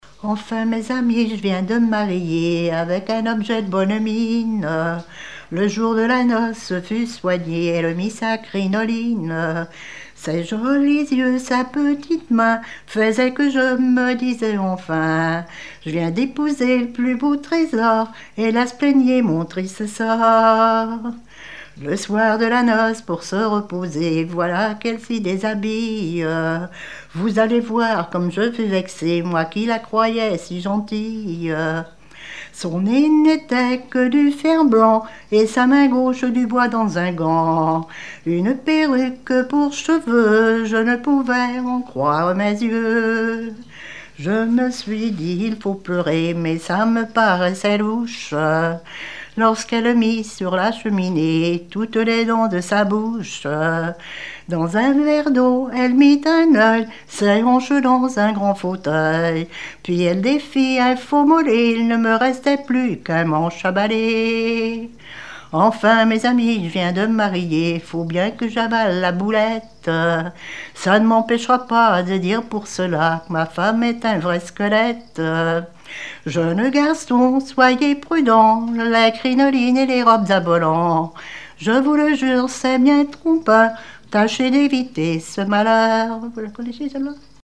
chanson fin XIXe siècle
Genre strophique
Pièce musicale inédite